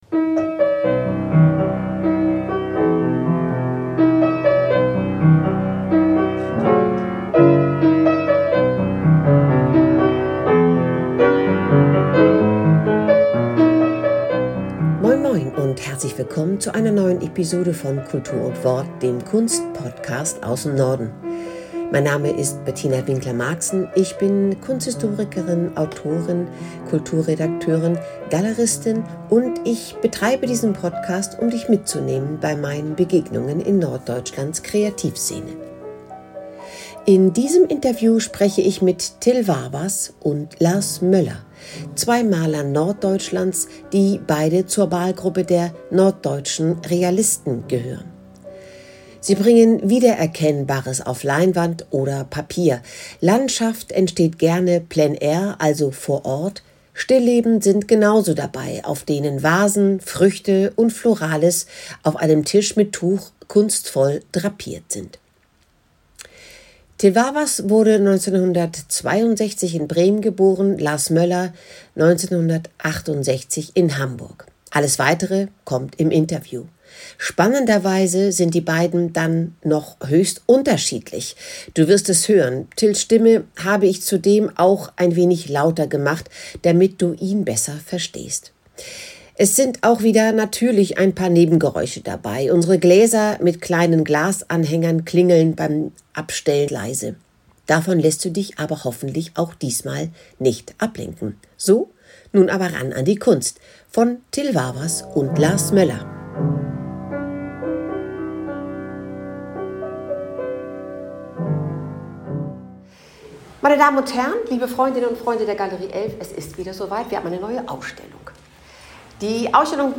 Alles Weitere kommt im Interview.